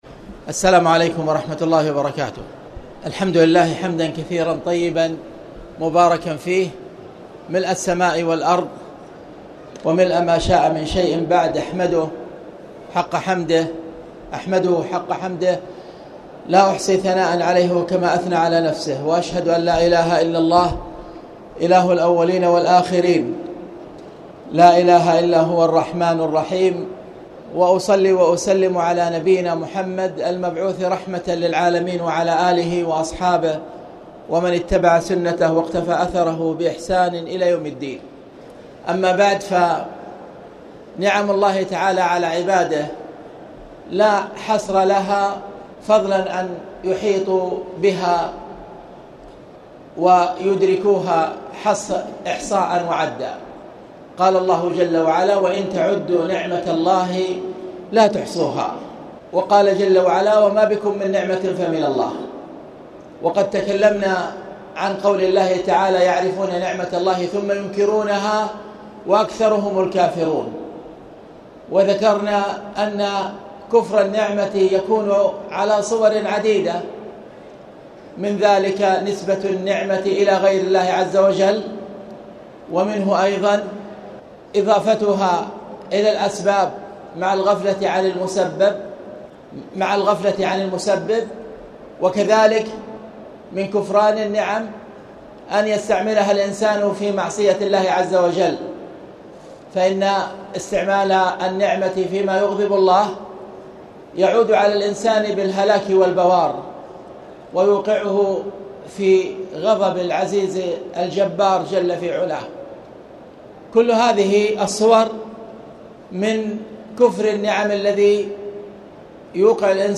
تاريخ النشر ١٤ رمضان ١٤٣٨ هـ المكان: المسجد الحرام الشيخ